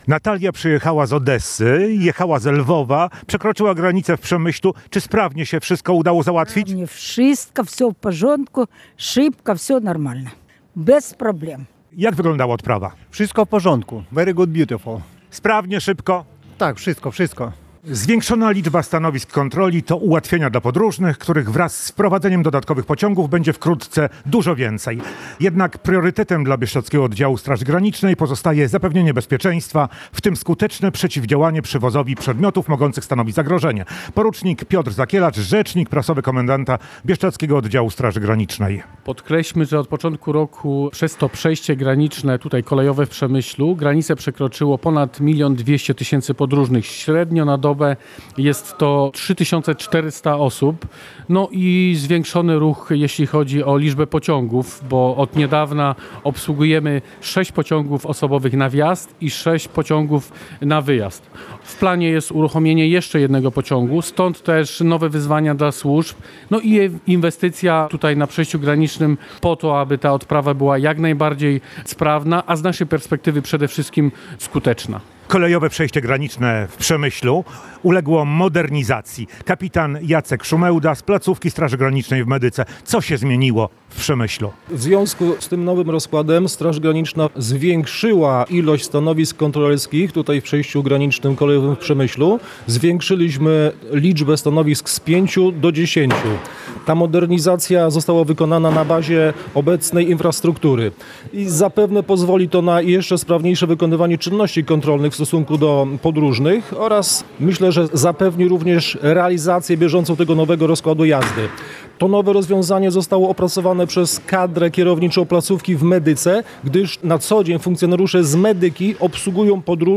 Relacje reporterskie • Podróżni przekraczający granicę na przejściu kolejowym w Przemyślu, korzystają już ze zmodernizowanej infrastruktury.